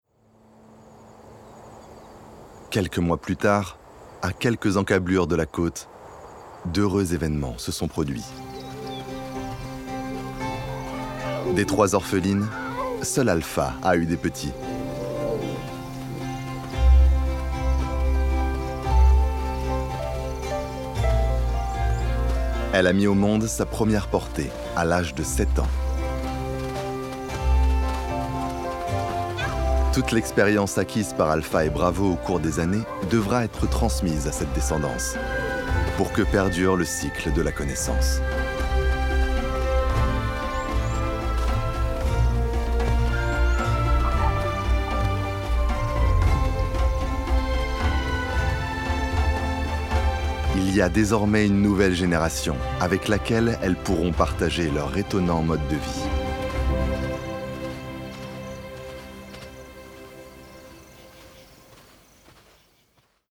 Narration Documentaire - Les lionnes de Namibie
- Basse